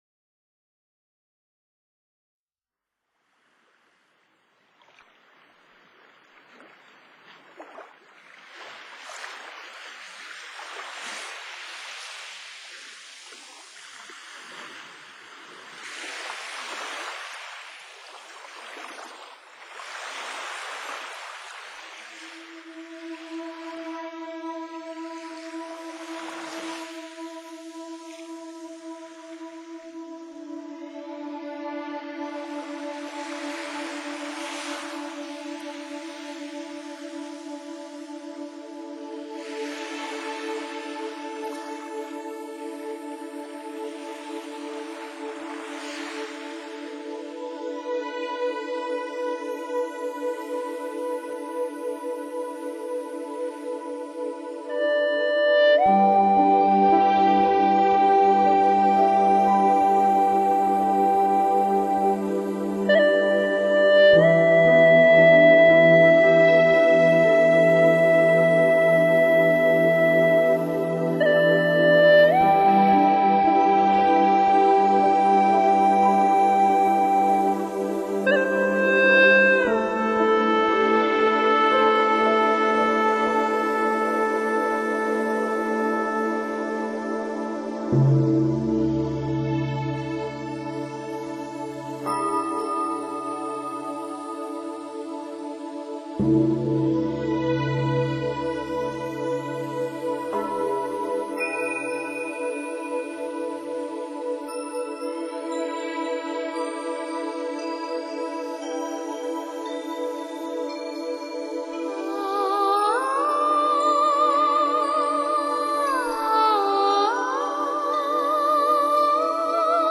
清新纯音 富含灵气的音符